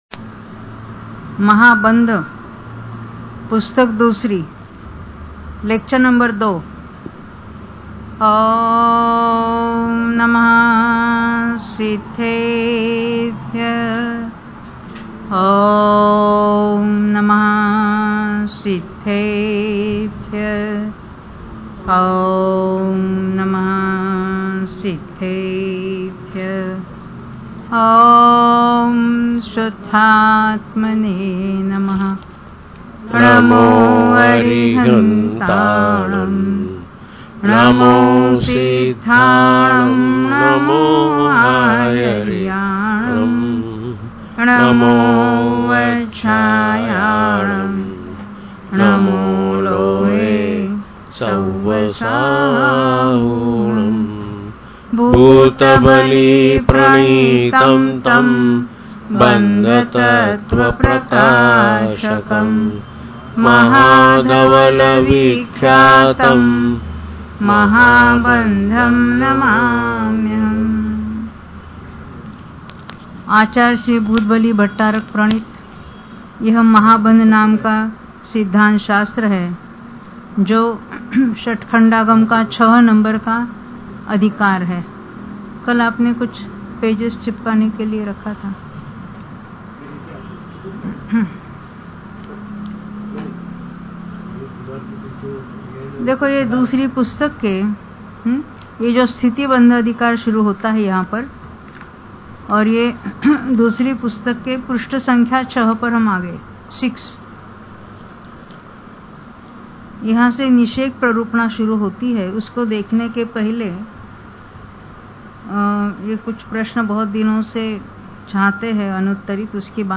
Pravachan